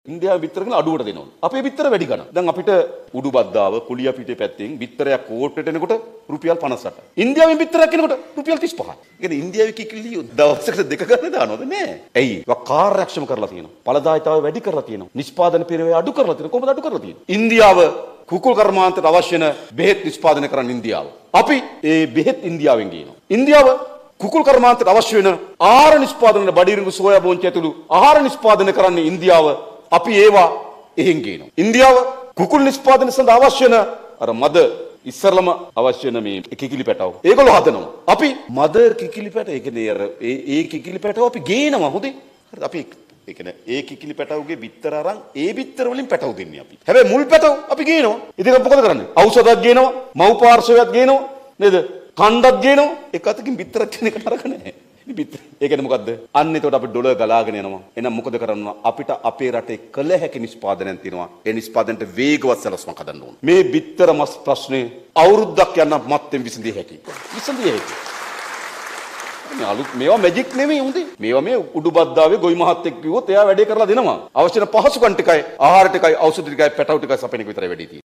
එහි නායක අනුර කුමාර දිසානායක මහතා මේ බව සදහන් කලේ කෝට්ටේ ආසන සමුළුවට එක්වෙමින් .